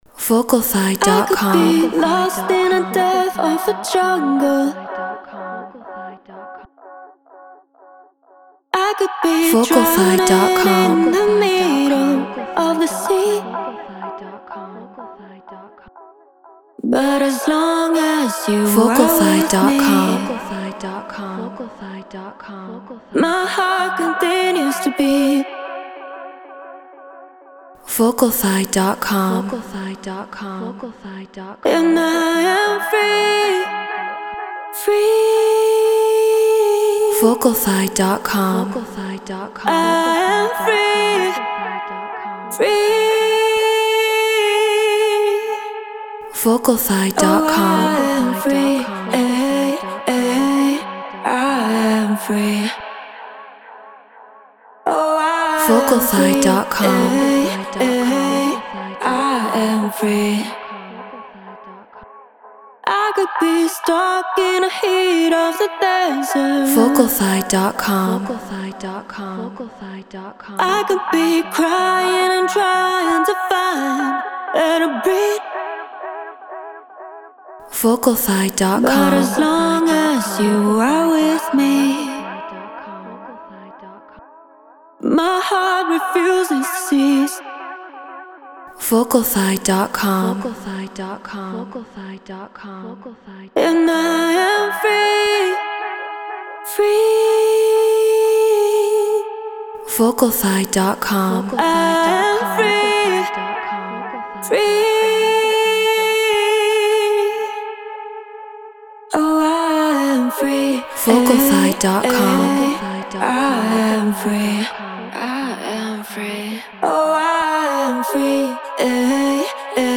Non-Exclusive Vocal.